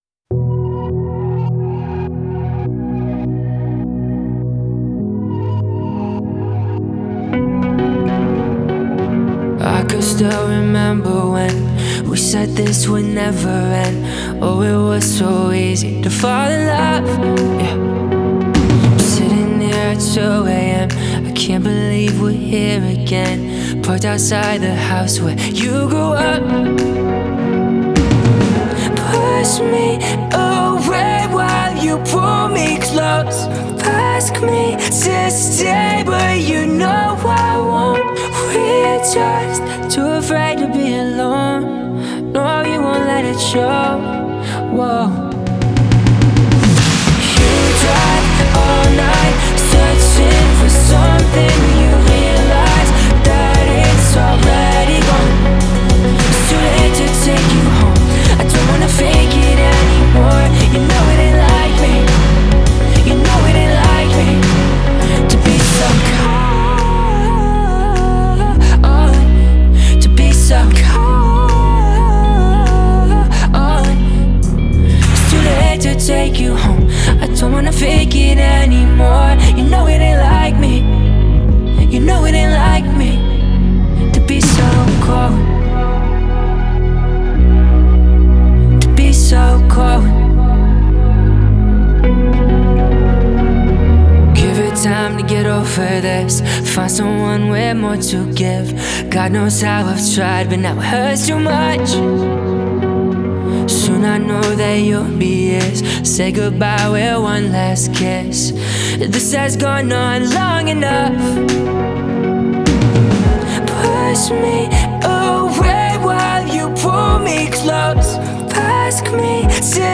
меланхоличная поп-баллада